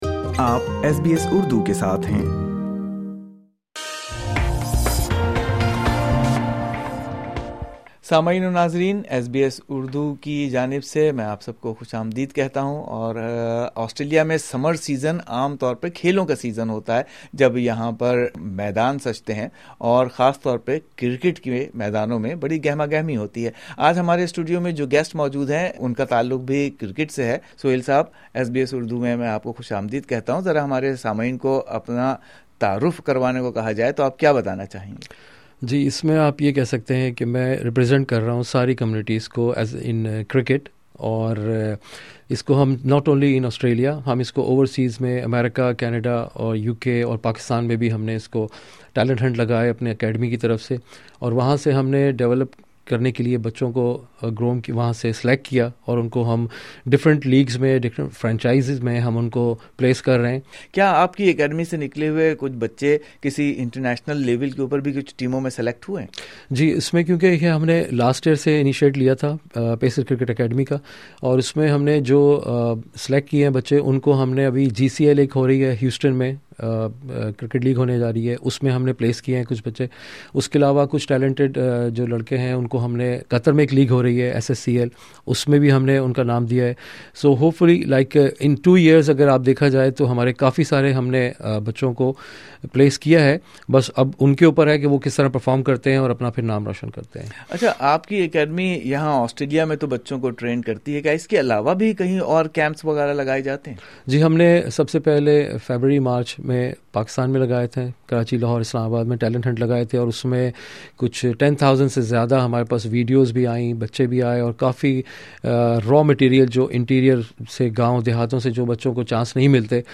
in the SBS podcast studio